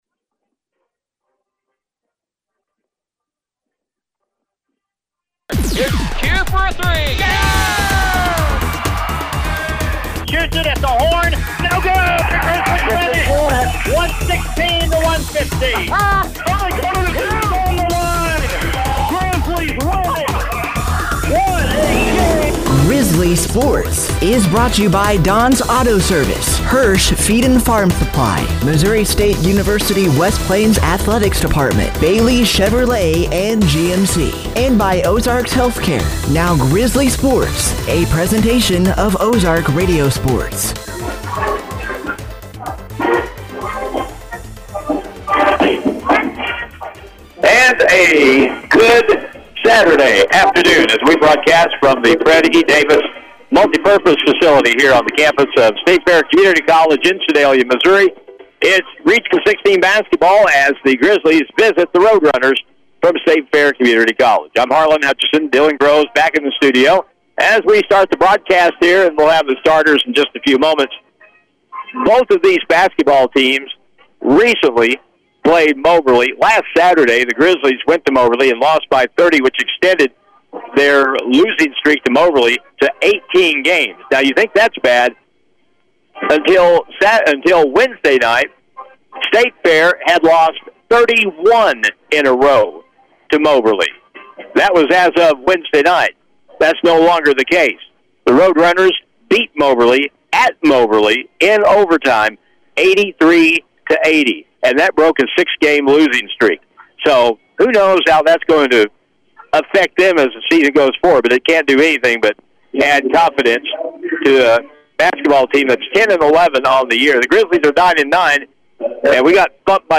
Game Audio Below: